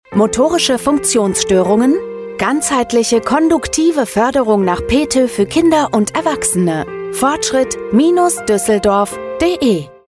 funkspot_fortschritt_duesseldorf.mp3